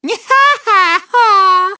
One of Lakitu's voice clips in Mario Kart 7